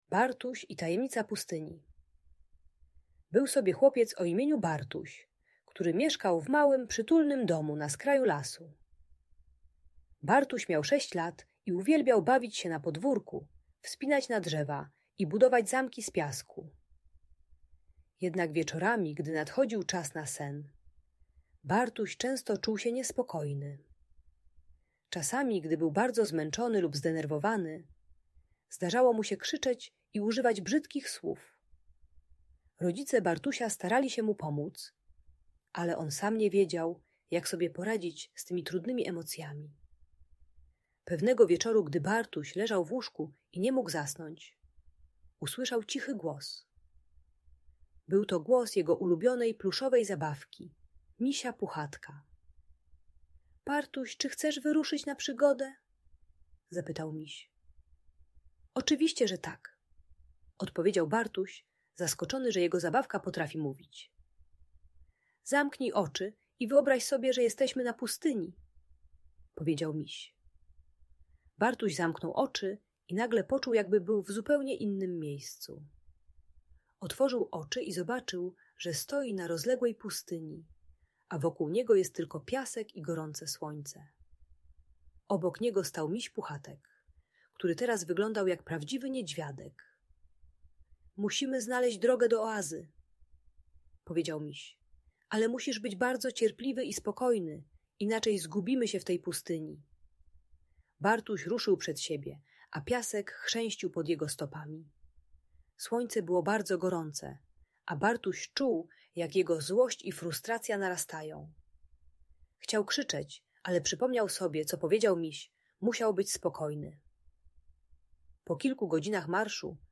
Bartuś i Tajemnica Pustyni - Niepokojące zachowania | Audiobajka